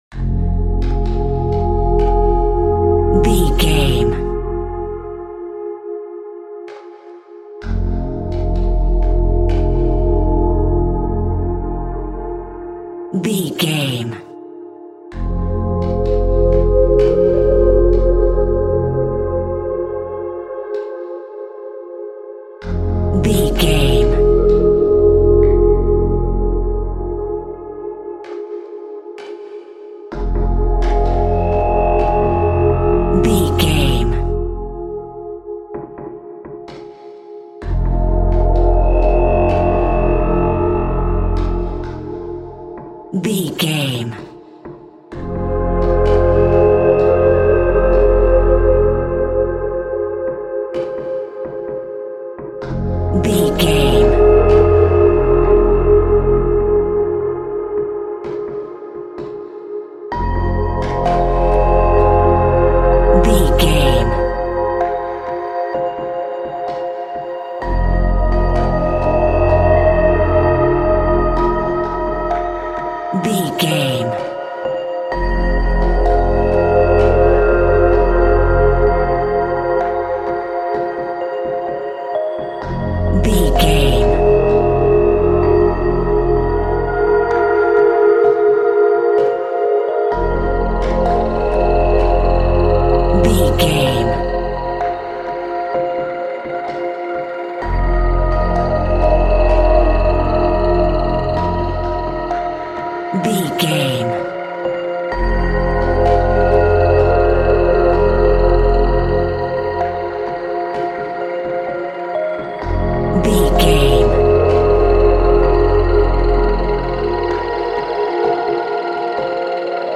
Aeolian/Minor
Slow
ambient
atmospheric
haunting
menacing
suspenseful